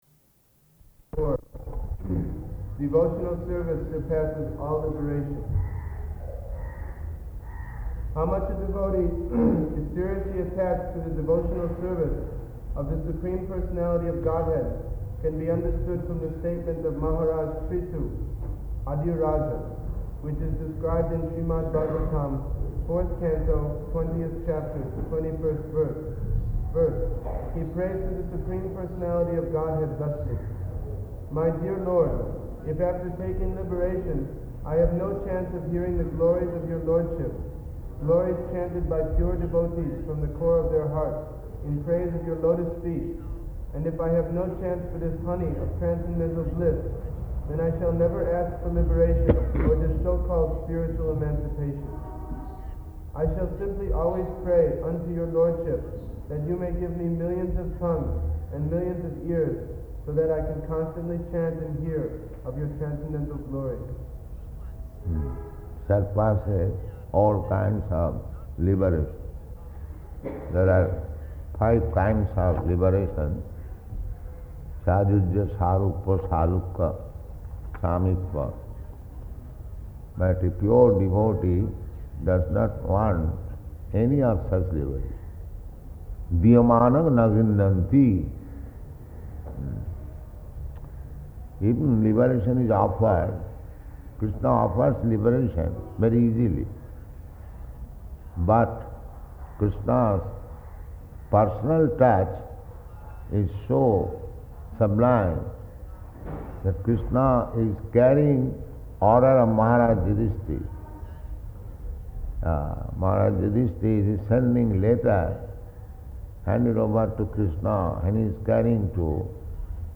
January 31st 1973 Location: Calcutta Audio file